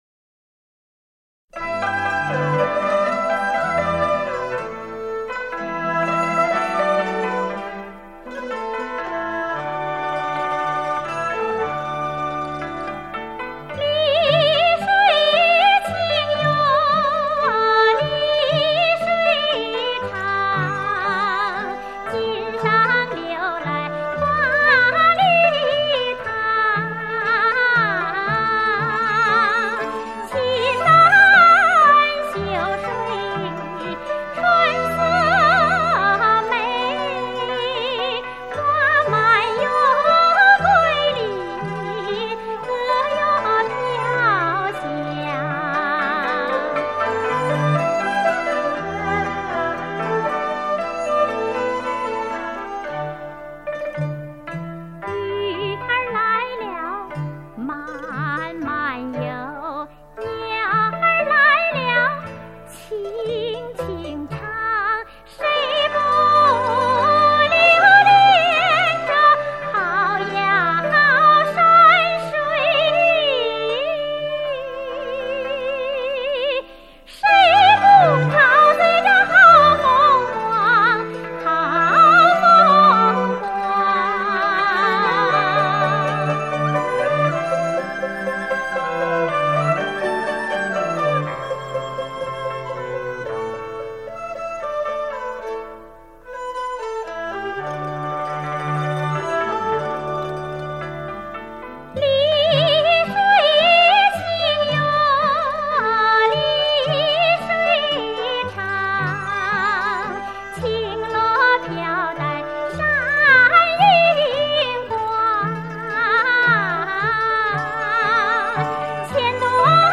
清新优美，朴实自然。